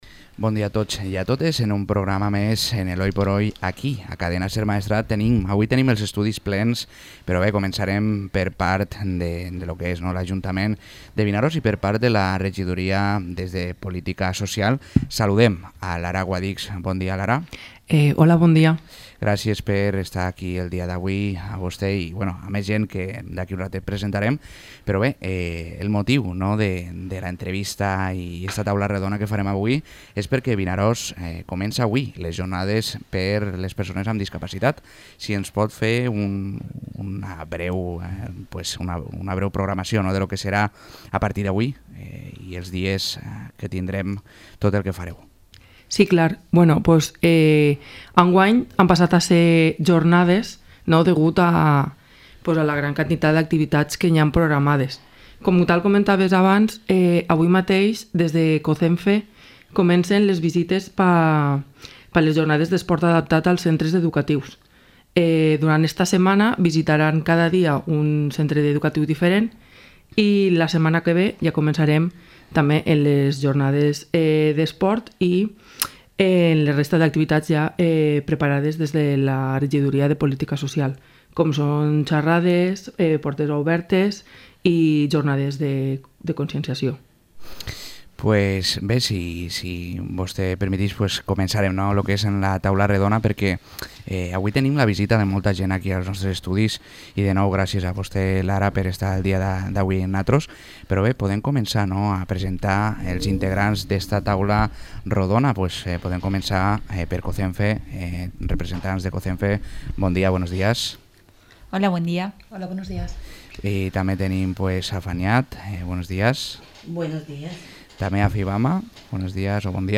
Taula rodona d'associacions de discapacitats